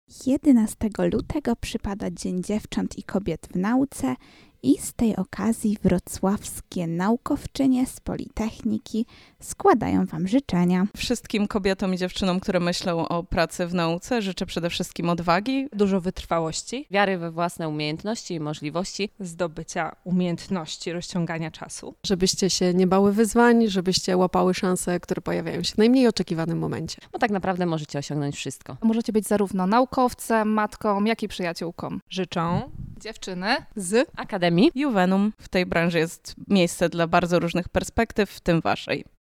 Dołączamy się do życzeń, które naukowczyniom składają badaczki z Academii Iuvenum:
dzien-kobiet-w-nauce-zyczenia.mp3